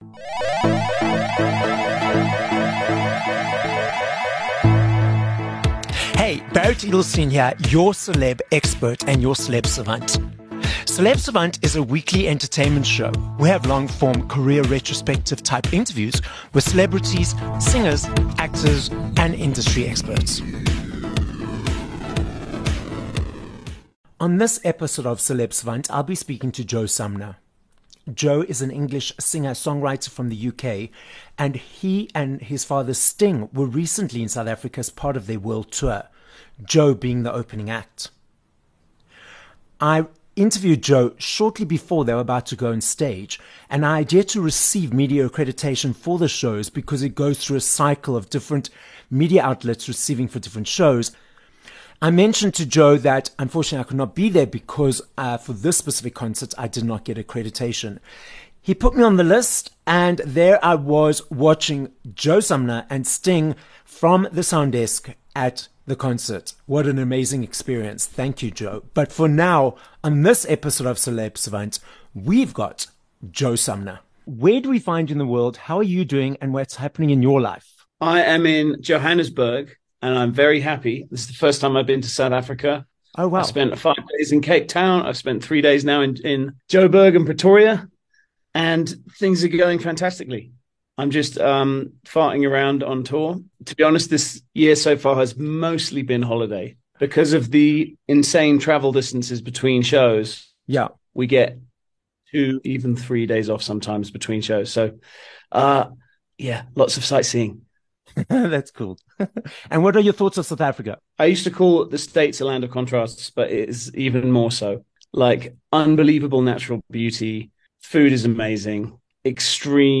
13 Feb Interview with Joe Sumner